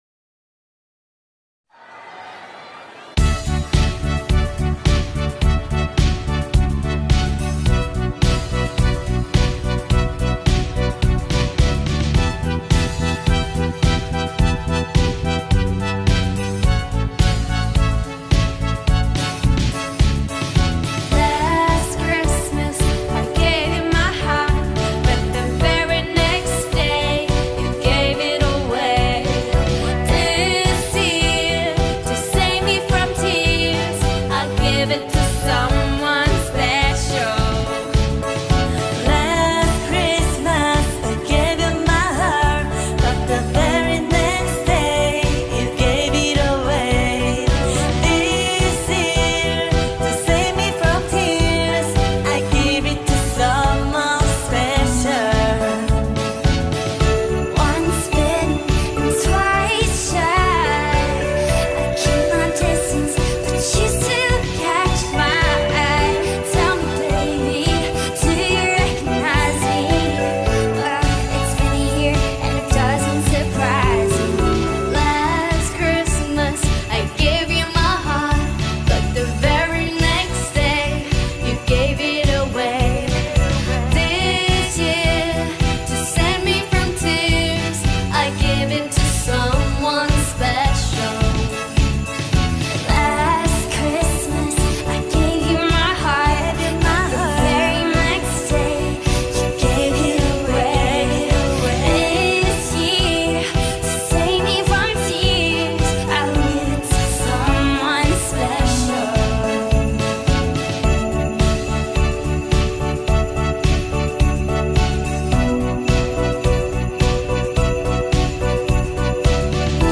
کی پاپ
آهنگ کریسمس